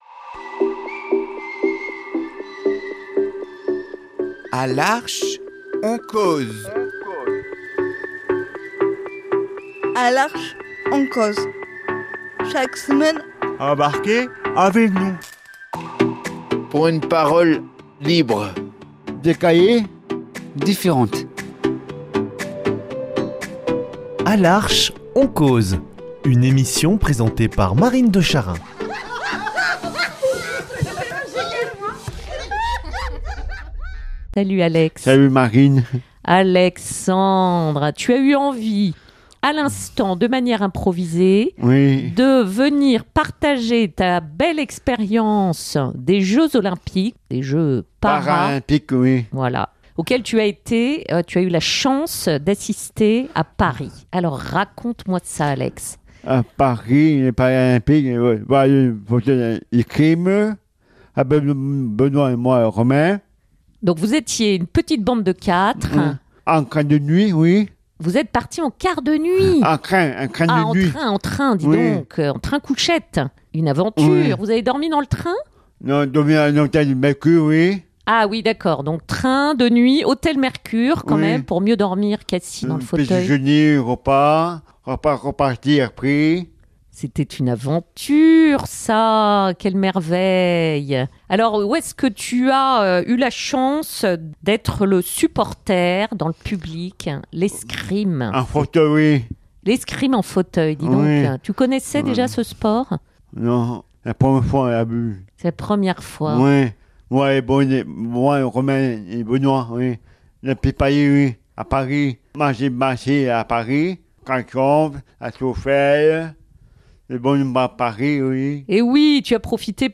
Une virée mémorable à Paris, chargé de spectacles, de visites, d’émotions, de fête. Au micro, le cœur tout vibrant, il raconte et nous contamine de sa ferveur.